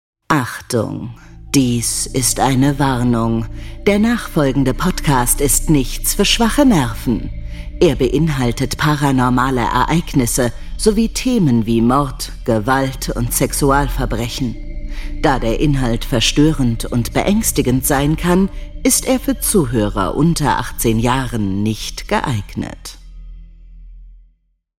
Eine Stimme, so warm, fesselnd, glaubwürdig und so angenehm, dass man sich von ihr umarmen lassen möchte.
Sprechprobe: Werbung (Muttersprache):